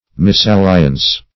Misalliance \Mis`al*li"ance\, n. [F. m['e]salliance.]